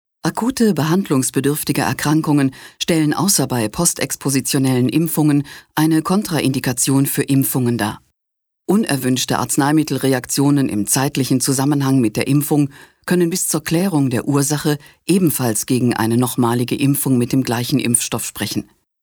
deutsche Sprecherin.
Sprechprobe: Industrie (Muttersprache):
female german voice over talent.